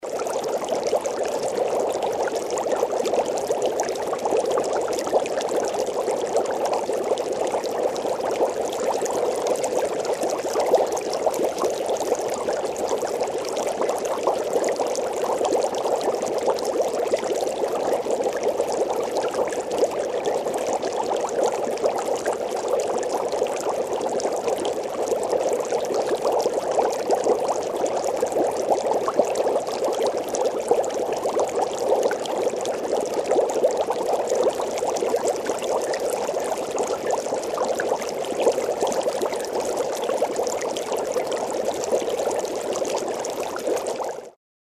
2. Интенсивное кипение воды